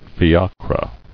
[fi·a·cre]